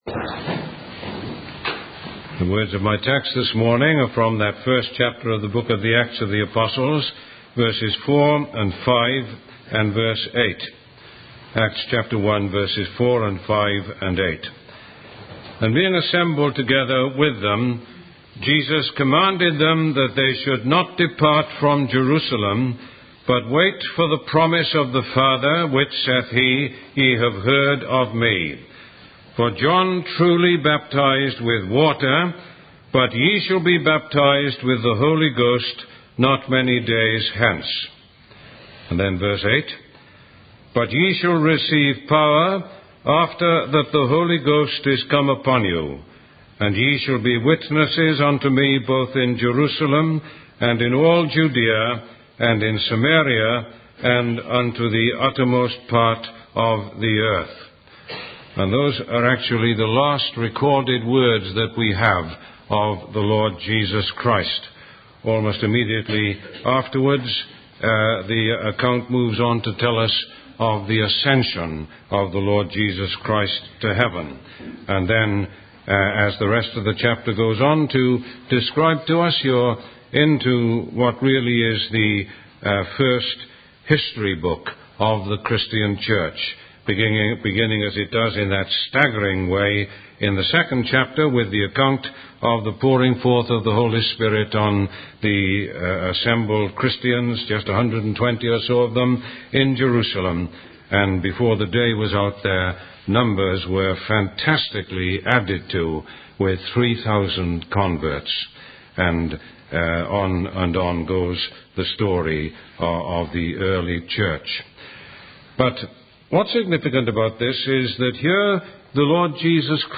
Sermon 2.mp3